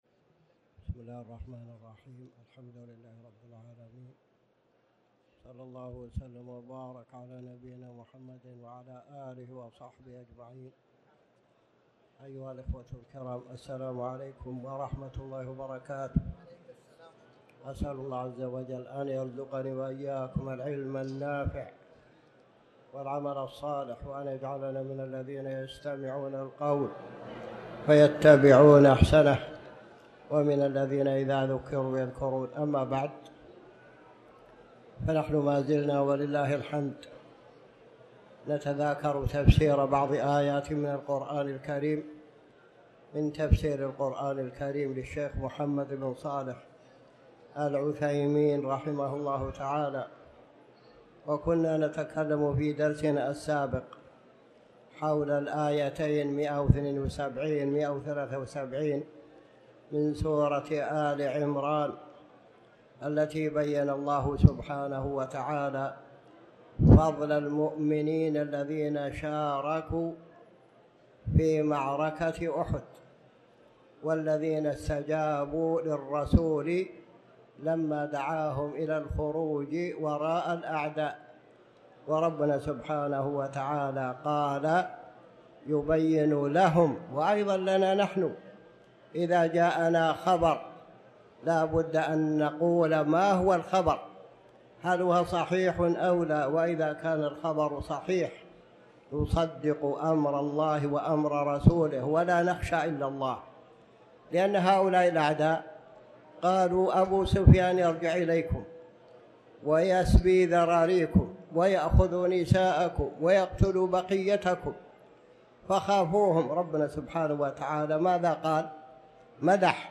تاريخ النشر ٢٥ ربيع الأول ١٤٤٠ هـ المكان: المسجد الحرام الشيخ